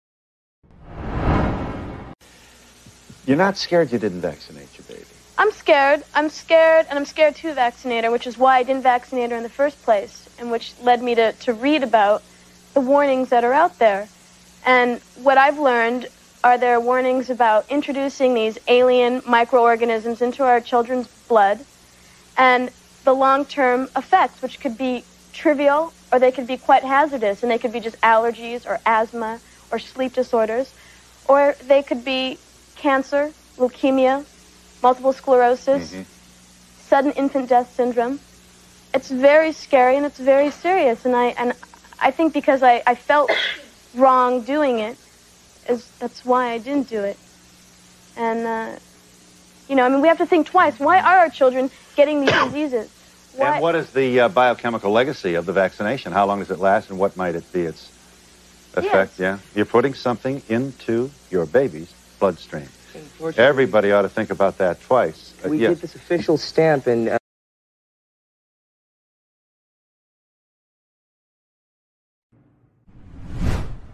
Actress Lisa Bonet Talks About Child Jabs on 80's Phil Donahue Talk Show